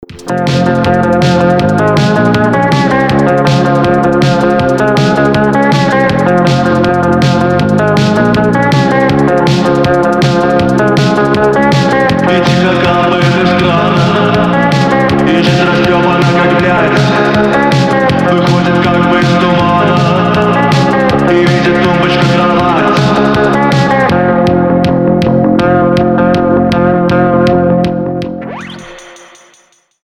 гитара
мужской голос
панк-рок
Пост-панк